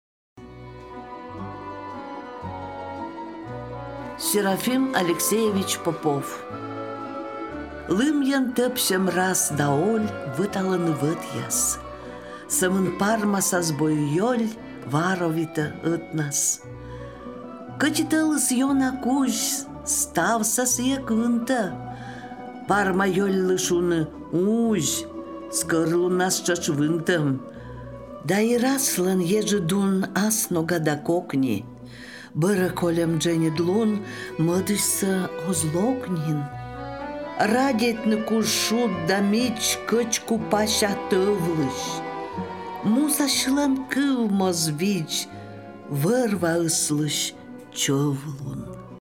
Кывбур
Лыддьӧ